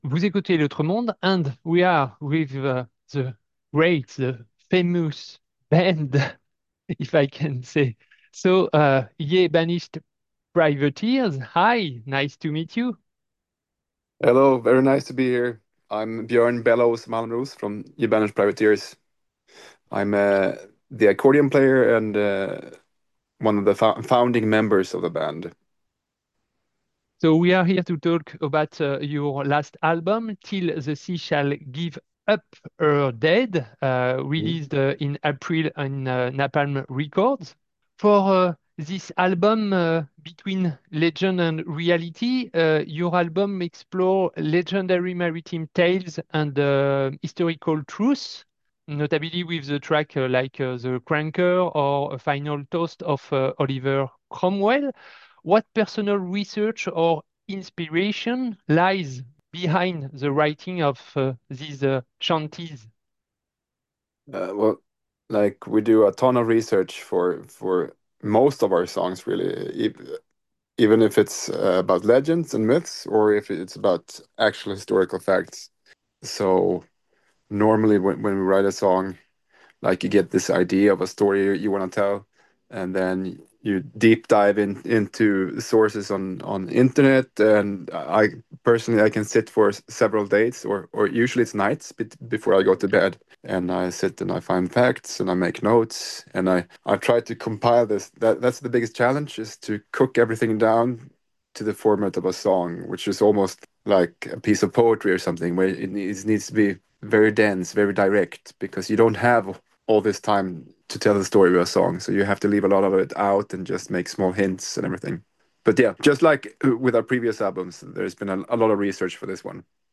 YE BANISHED PRIVATEERS - itw - 25.06.2025